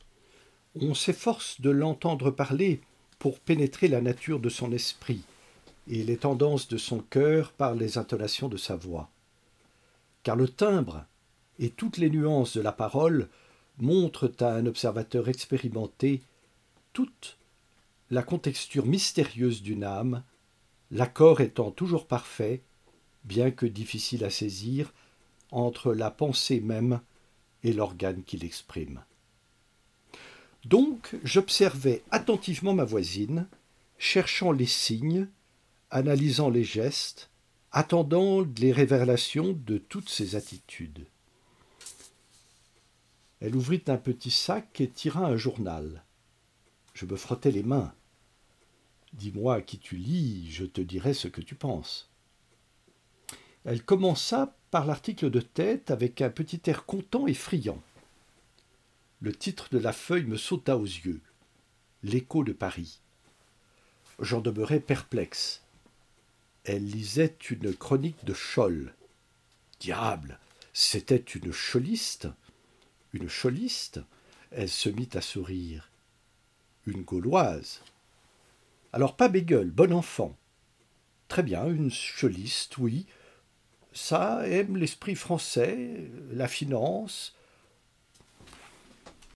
Narrateur
Voix off
- Baryton-basse